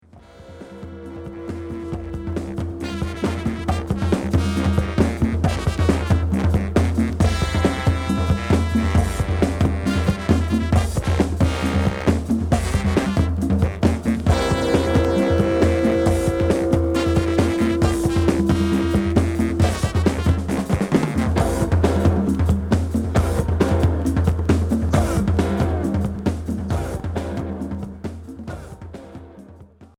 Groove pop psychédélique